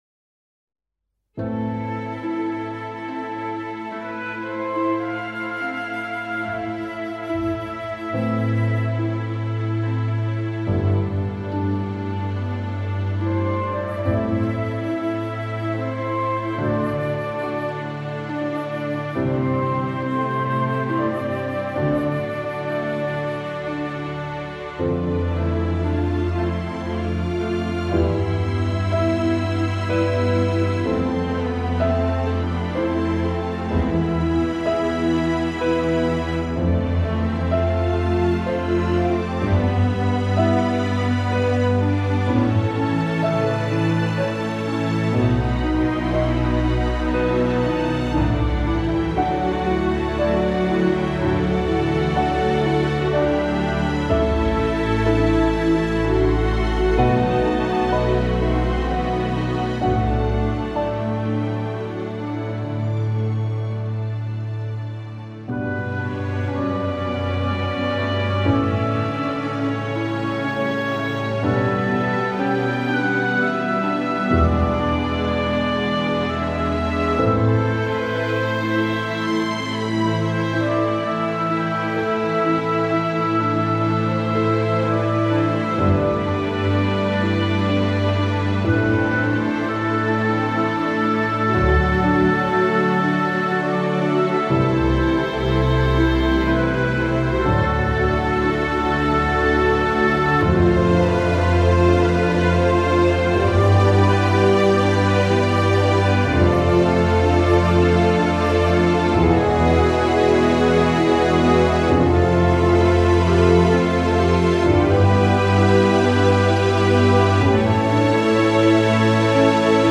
aerien - nostalgique - calme - piano - nappes